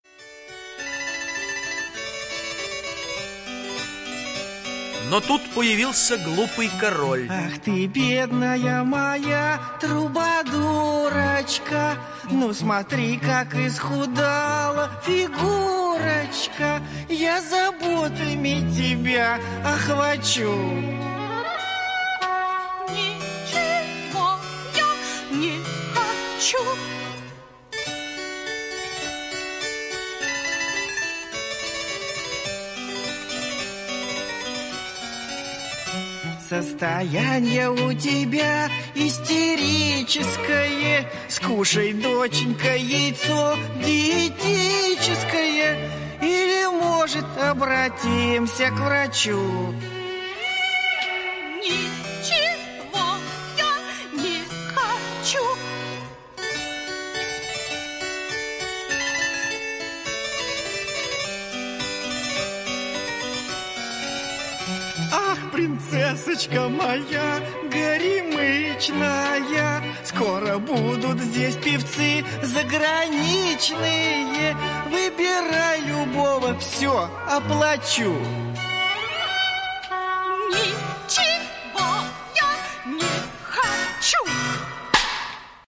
звучит на клавесине и просто завораживает.